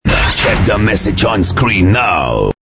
File Category : Free mobile ringtones > > Sms ringtones